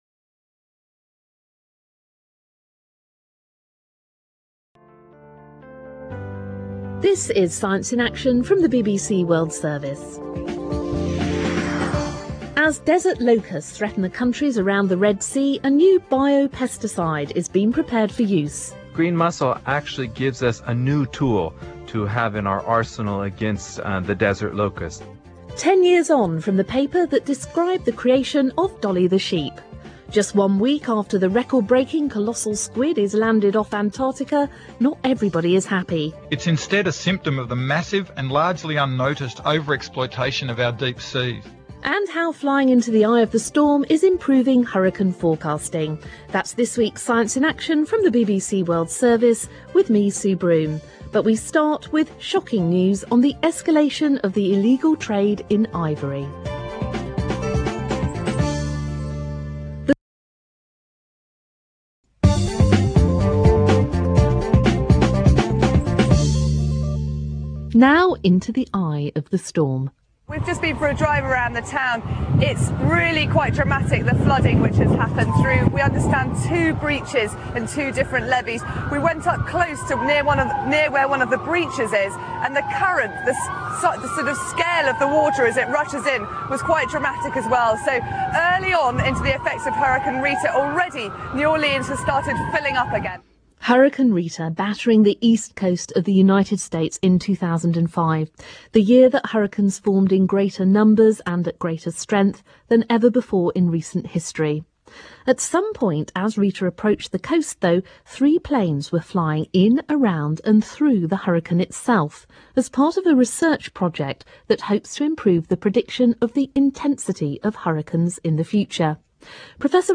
He was interviewed by NPR and the